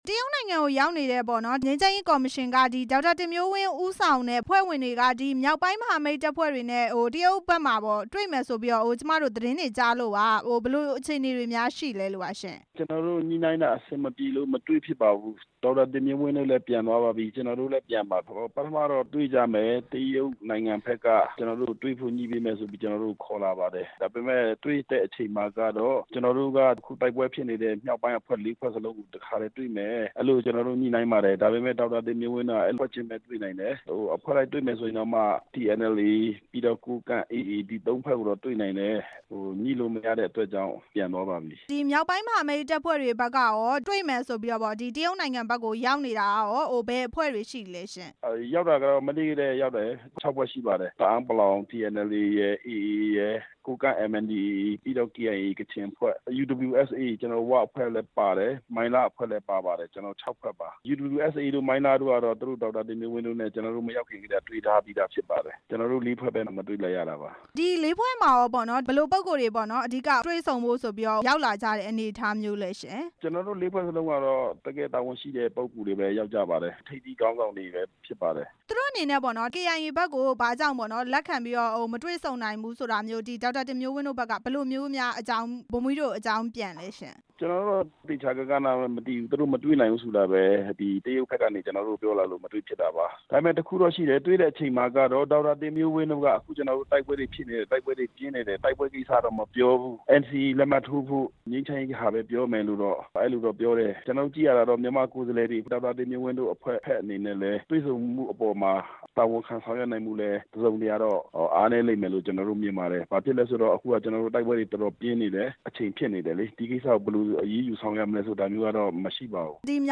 ငြိမ်းချမ်းရေးကော်မရှင်နဲ့တွေ့ဆုံဖို့ မေးမြန်းချက်